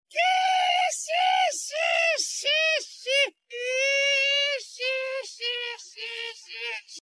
Download “gol d roger laugh” gol-d-roger-laugh.mp3 – Downloaded 5302 times – 213.20 KB